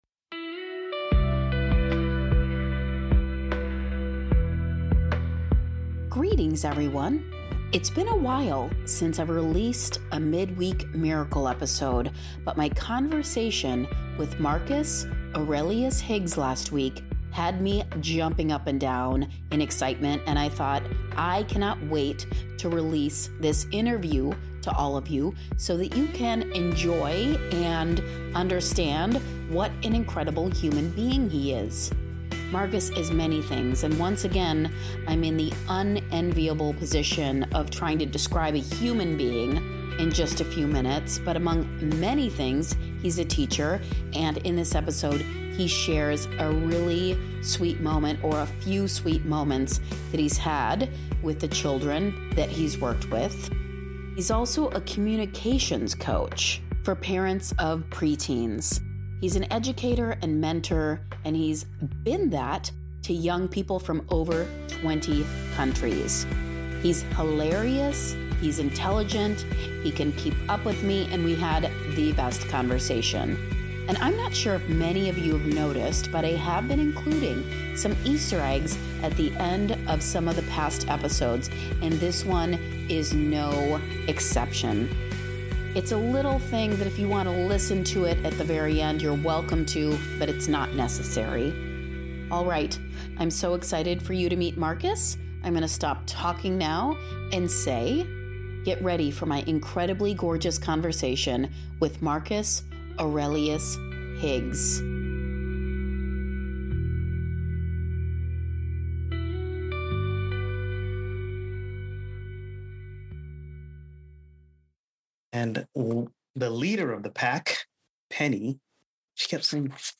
There is so much laughter in this episode, and kindness, and sweetness that you should all be shining by the end of listening to what he and I created!